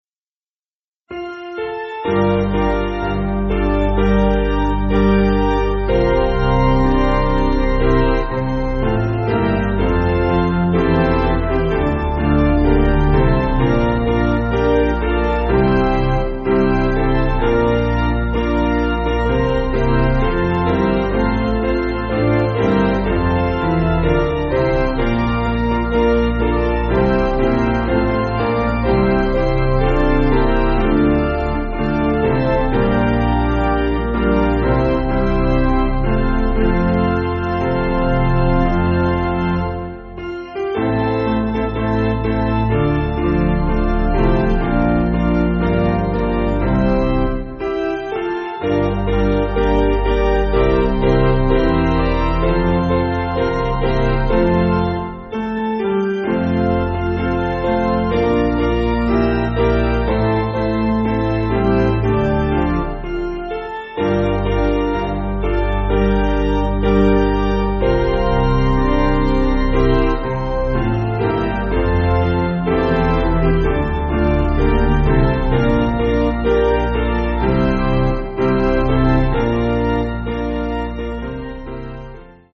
Hymn books
Basic Piano & Organ